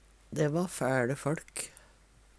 fæL - Numedalsmål (en-US)